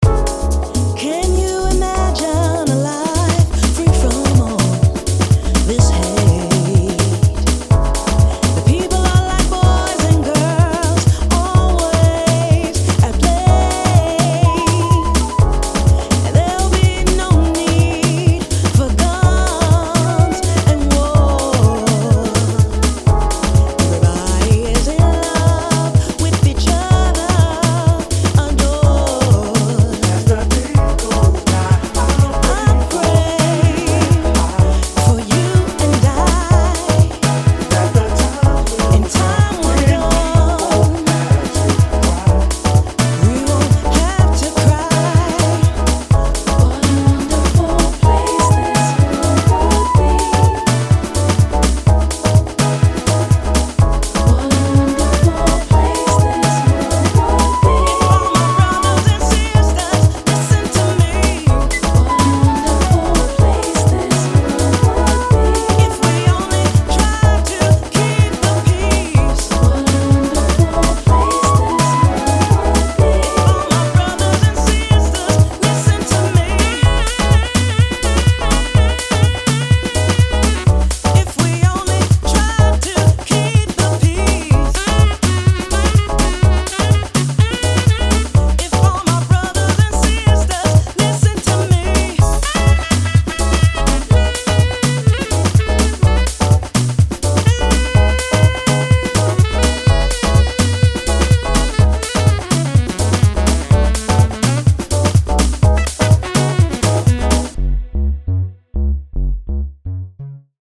house diva